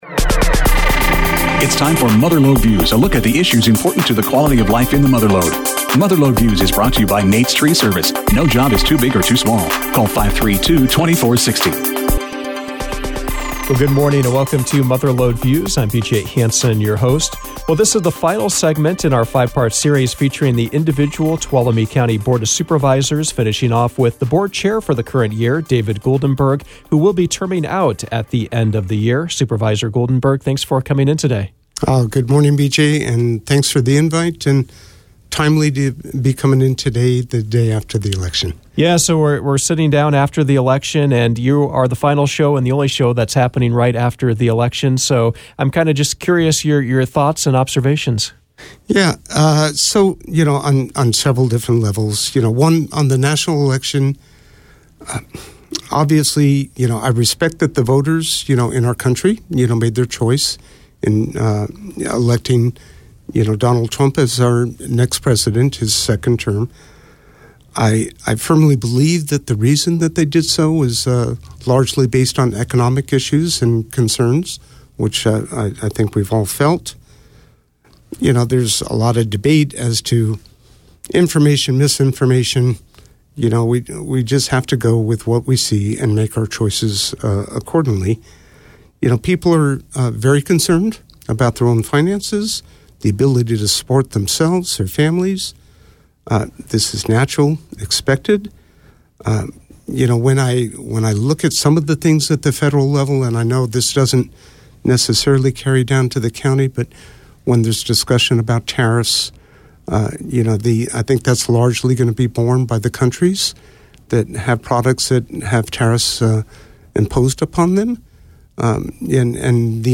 District One Tuolumne County Supervisor David Goldemberg was the guest on Mother Lode Views.